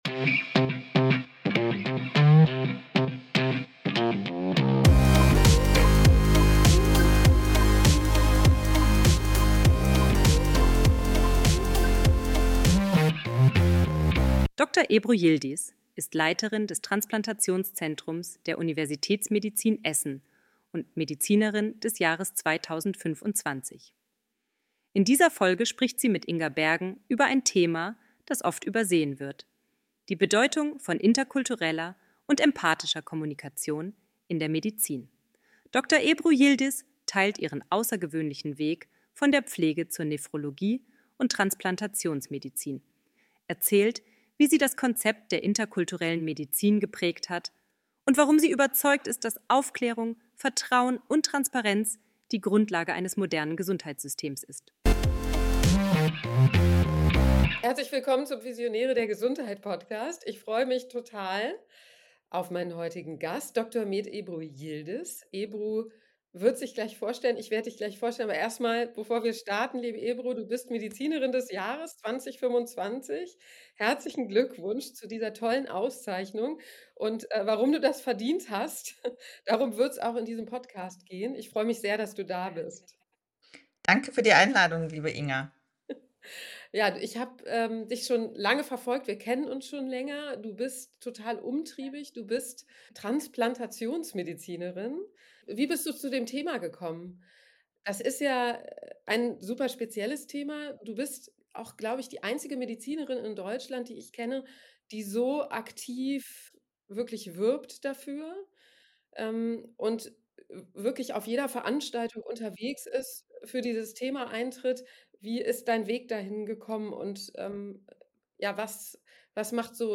Ein inspirierendes Gespräch über Menschlichkeit, Vielfalt und die Zukunft der Medizin.